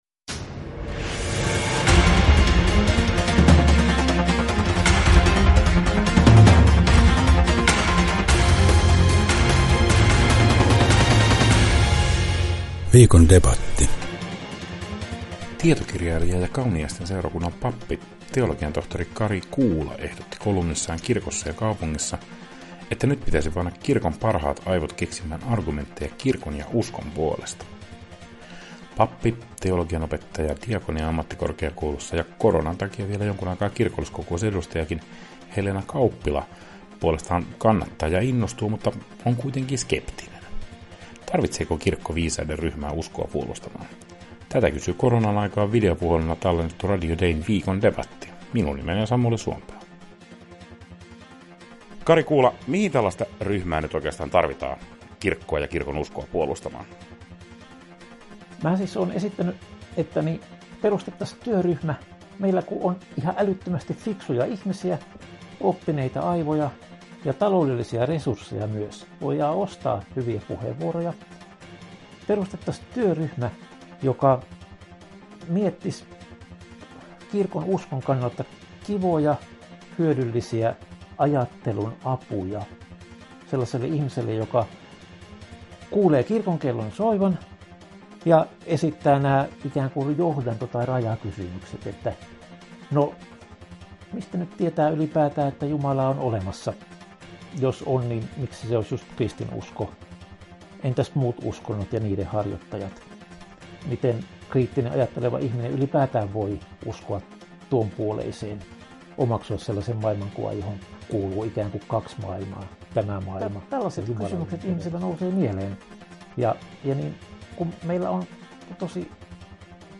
KUUNTELE: Viikon debatti kysyy: Tarvitseeko kirkko viisasten kerhon?